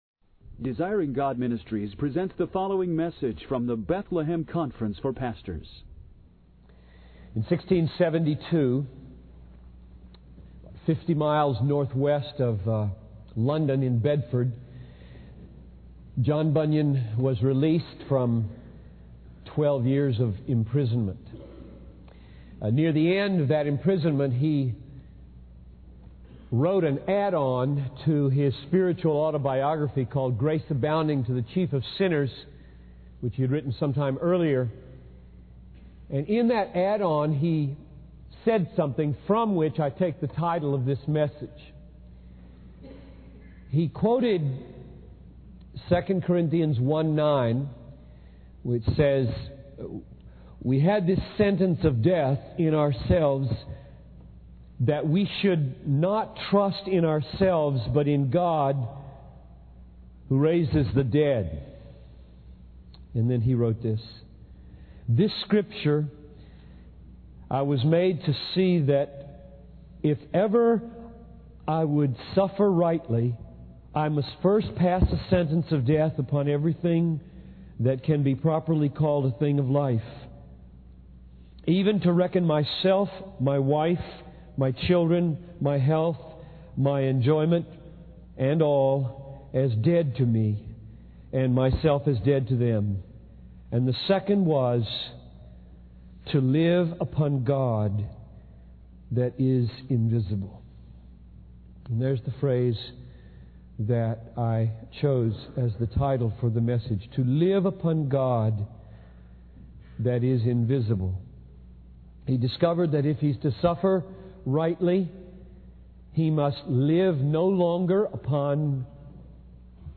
In this sermon, the speaker discusses the life and sufferings of John Bunyan, a 17th-century English writer and preacher.